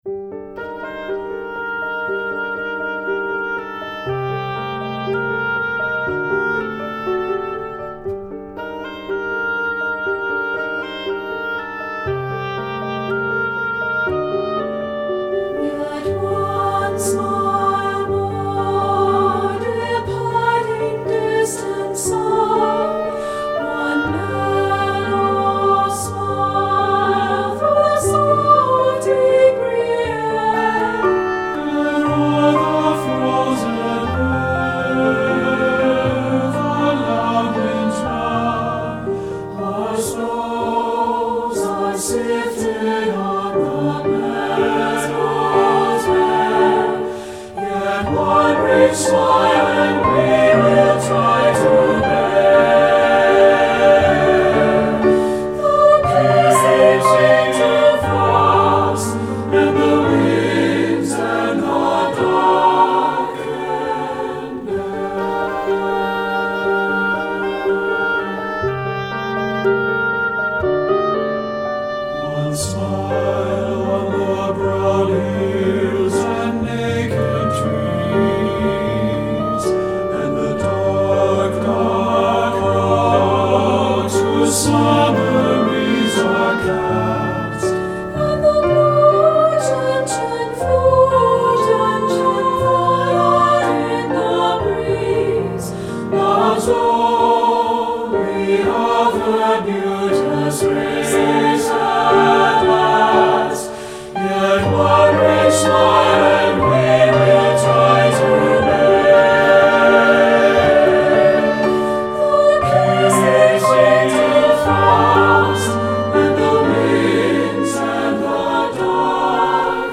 Choral Concert/General
SATB